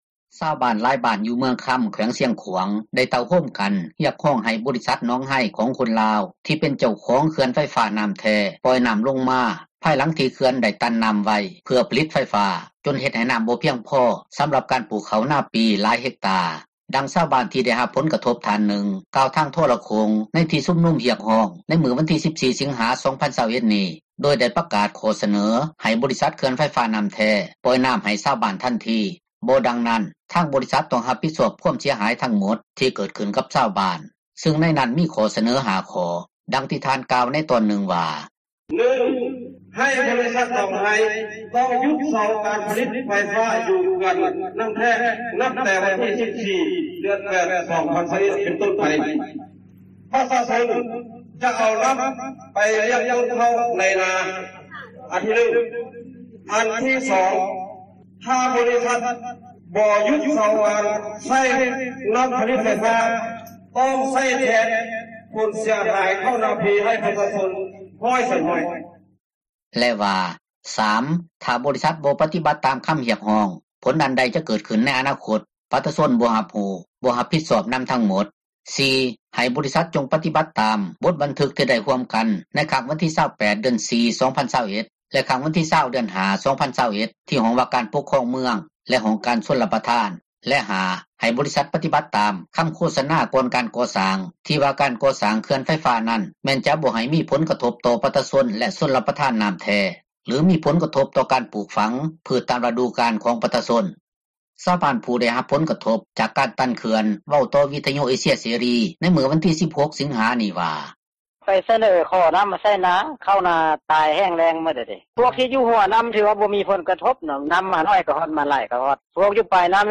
ຊາວບ້ານຜູ້ໄດ້ຮັບຜົລກະທົບ ຈາກການຕັນເຂື່ອນ ເວົ້າຕໍ່ວິທຍຸເອເຊັຍເສຣີ ໃນມື້ວັນທີ 16 ສິງຫານີ້ວ່າ: